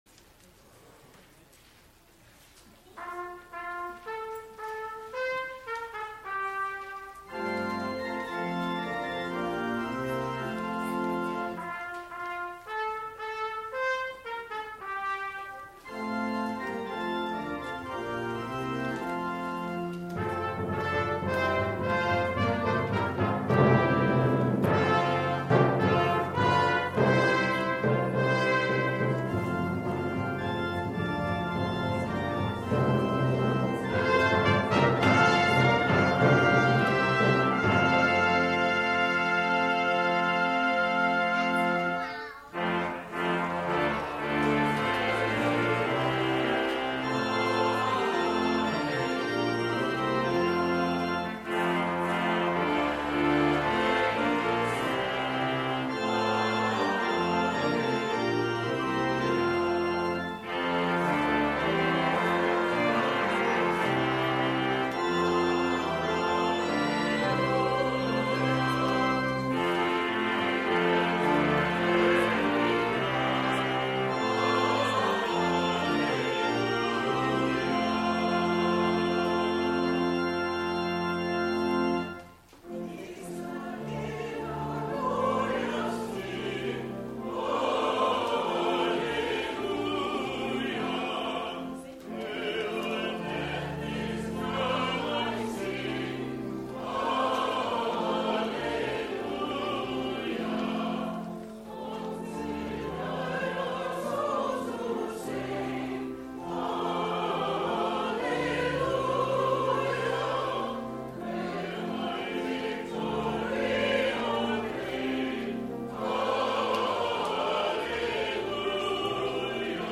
Click here to listen to the music from our Easter service.
Each Sunday from September to the middle of June, the choir fills the church with music from the rich Anglican tradition to quality spirituals and gospel music.
Our singers range from age 9 to 90.
organ
St-Stephens-Easter-Music-20141.mp3